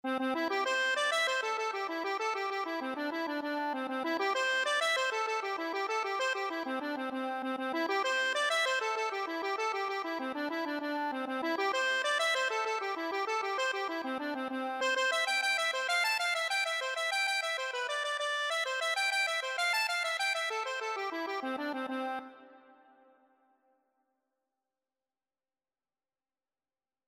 Traditional Music of unknown author.
6/8 (View more 6/8 Music)
C5-A6
C major (Sounding Pitch) (View more C major Music for Accordion )
Accordion  (View more Easy Accordion Music)
Traditional (View more Traditional Accordion Music)